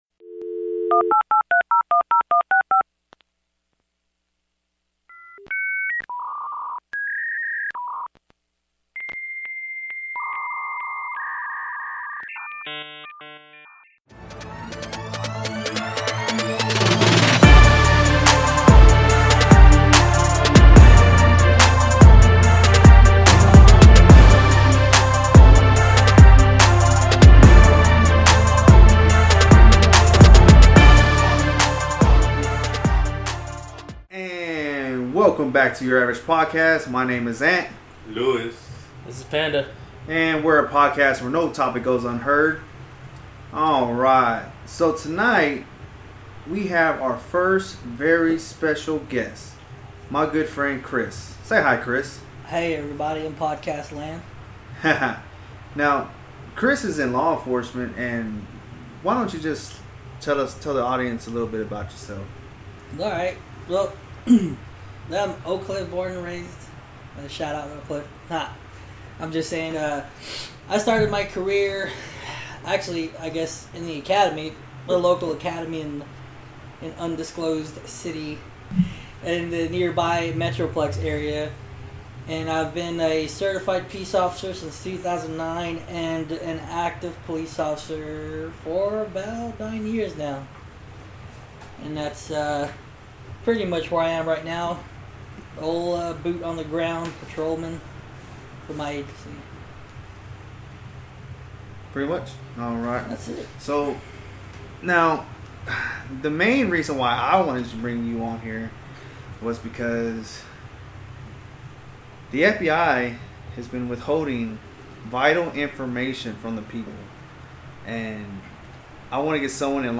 We basically just shot the shit with him, asked a couple of questions but most of it was unscripted fun.